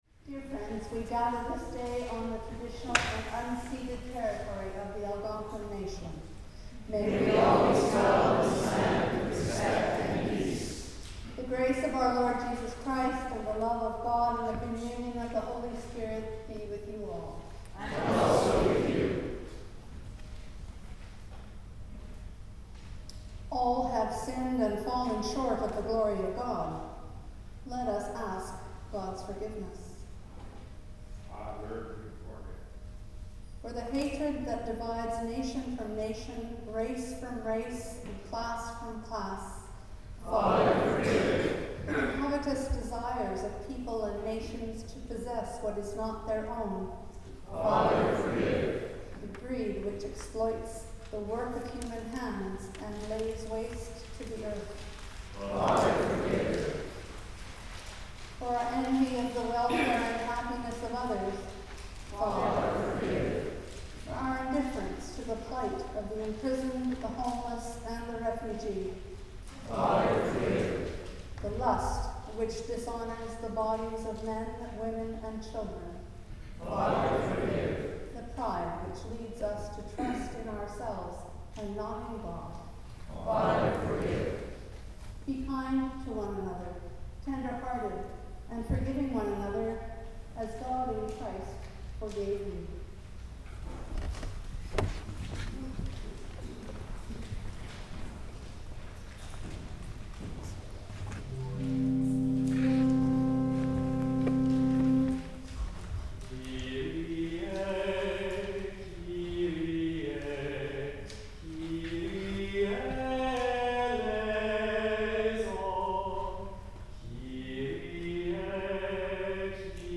The Lord’s Prayer (sung)